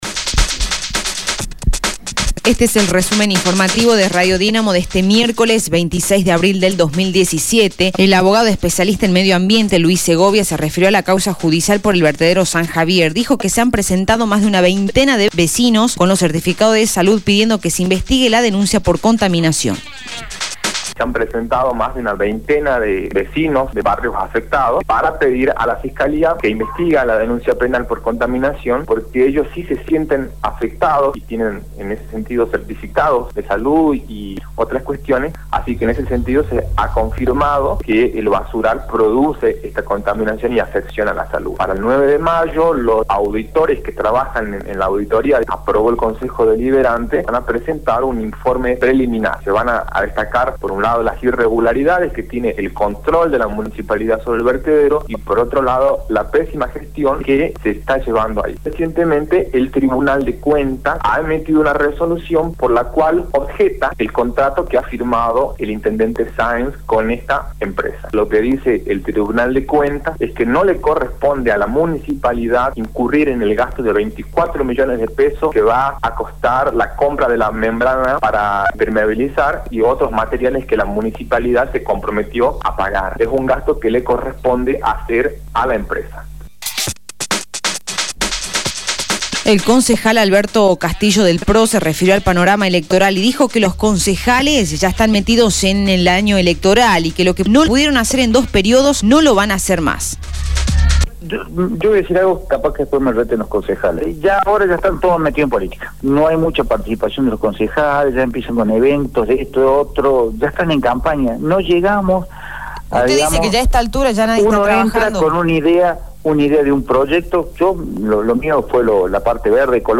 Resumen Informativo de Radio Dinamo del día 26/04/2017 2° Edición